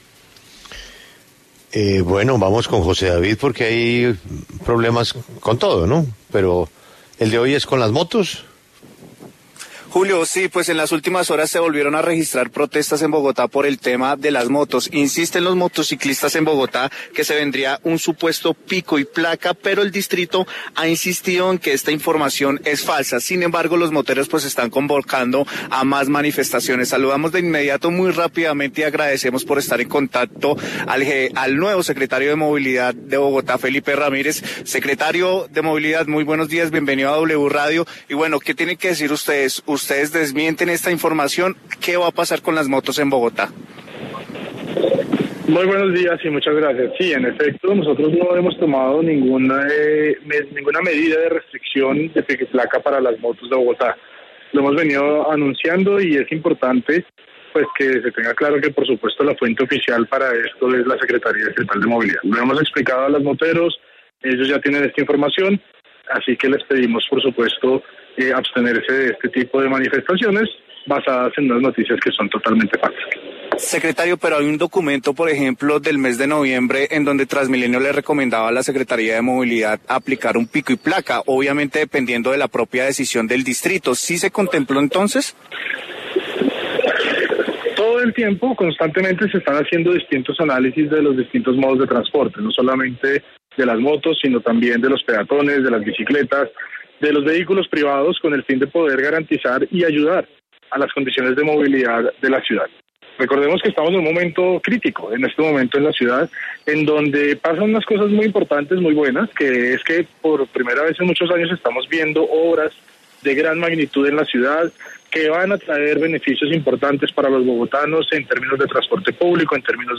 En diálogo con La W, el secretario de Movilidad de Bogotá, Felipe Ramírez, aseguró que es falso que el Distrito esté considerando la posibilidad de aplicar pico y placa a las motos.